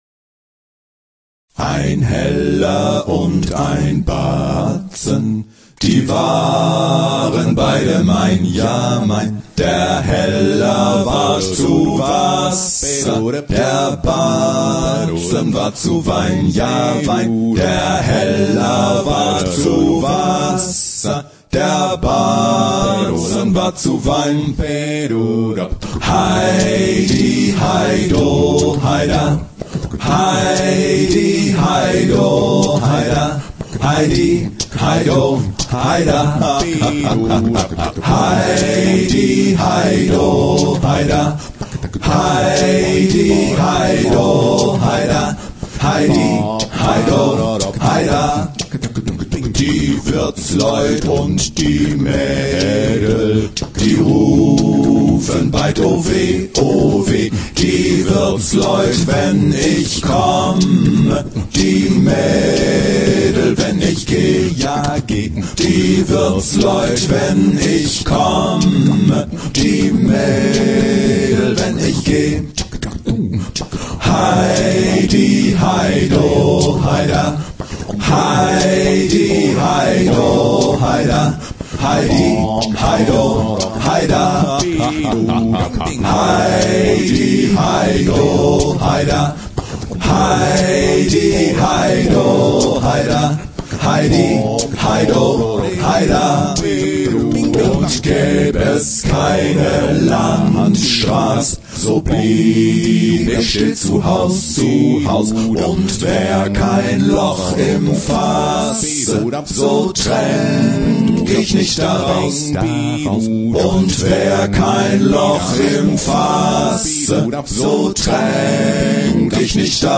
Действительно, тот поистине мефистофельский хохот, который мы слышим в припеве, впечатлит кого угодно…
в современном оригинальном исполнении